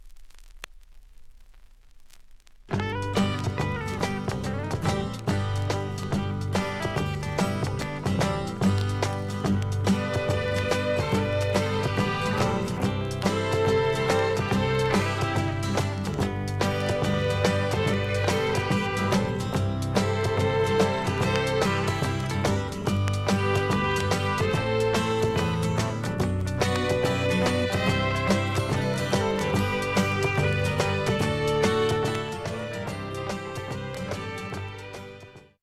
３回までのかすかなプツが２箇所 単発のかすかなプツが１７箇所